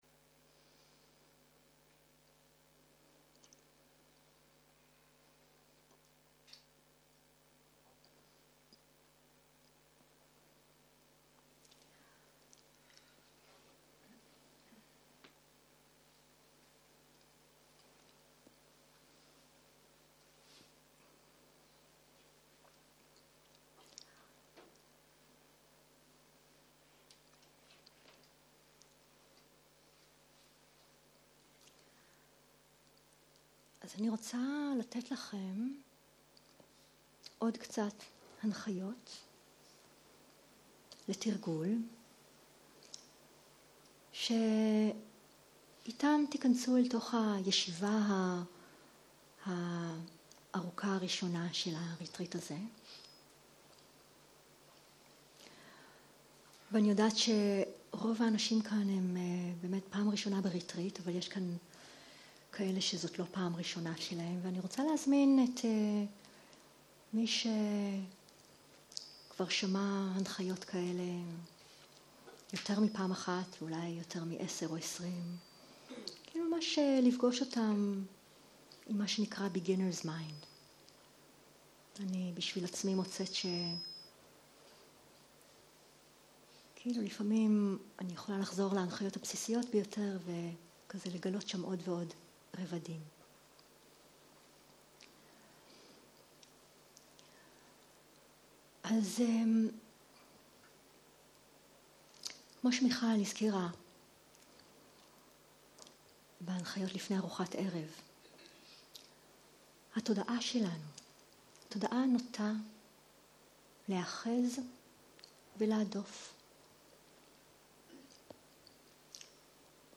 12.01.2023 - יום 1 - ערב - הנחיות מדיטציה - הקלטה 1